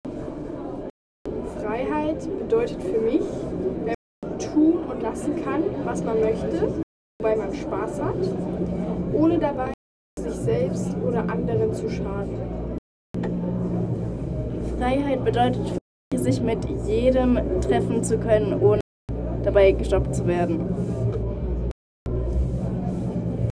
Standort der Erzählbox:
Stendal 89/90 @ Stendal